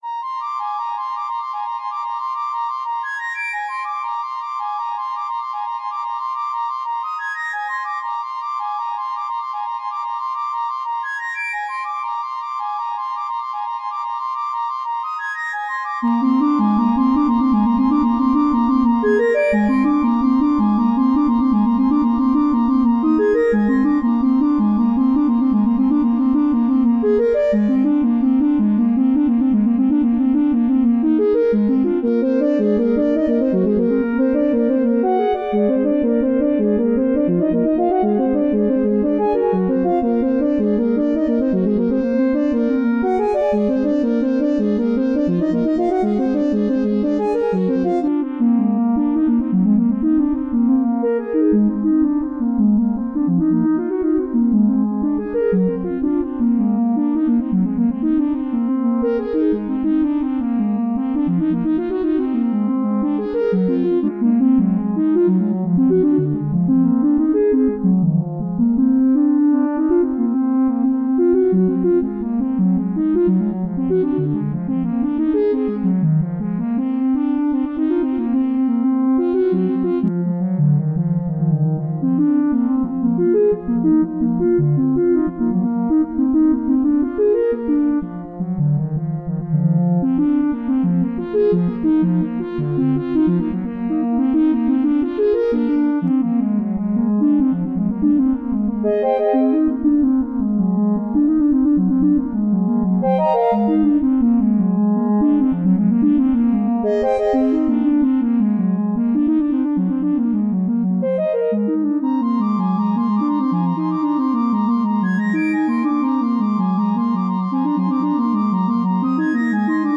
Experimental rapid-fire electronic compositions.